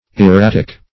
Erratic \Er*rat"ic\, a. [L. erraticus, fr. errare to wander: cf.